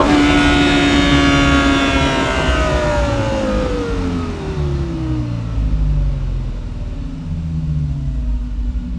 rr3-assets/files/.depot/audio/Vehicles/v10_04/v10_04_decel.wav
v10_04_decel.wav